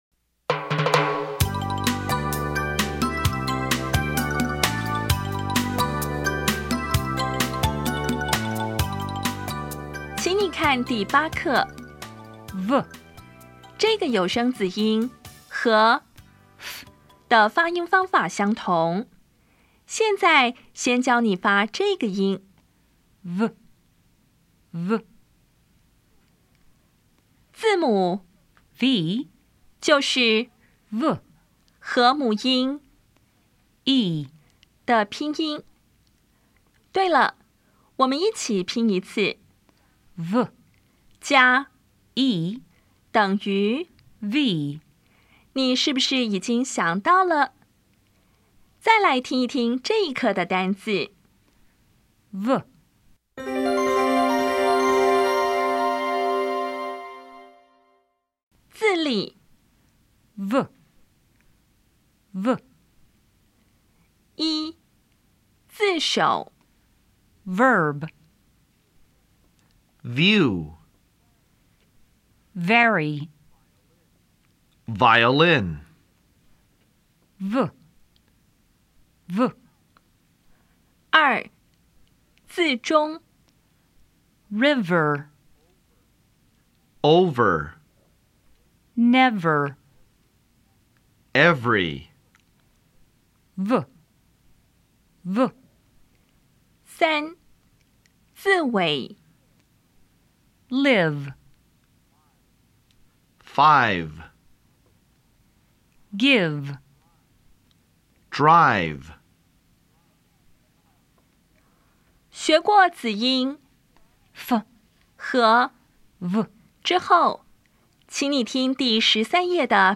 当前位置：Home 英语教材 KK 音标发音 子音部分-2: 有声子音 [v]
音标讲解第八课
比较[f][v]            [f](无声) [v](有声)
比较[b][v]           [b](有声) [v] (有声)
Listening Test 4